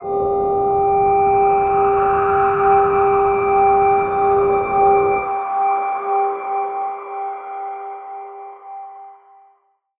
G_Crystal-G6-mf.wav